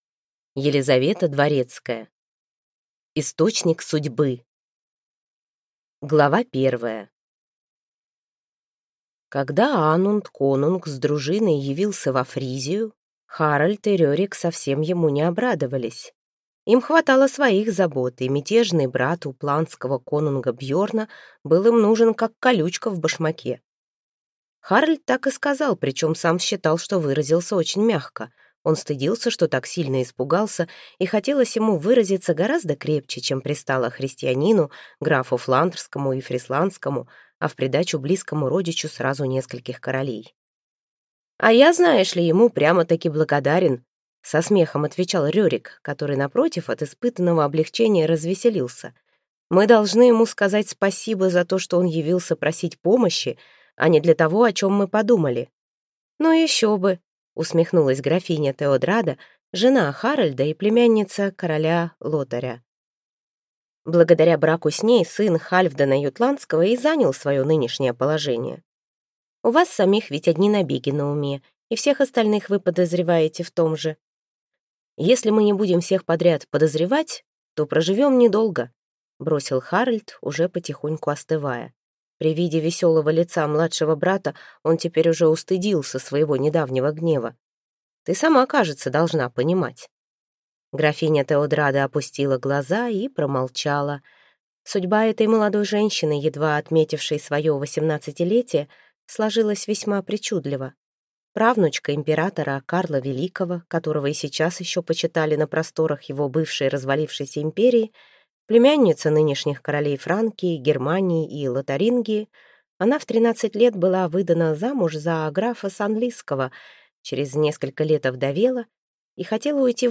Аудиокнига Источник судьбы | Библиотека аудиокниг
Прослушать и бесплатно скачать фрагмент аудиокниги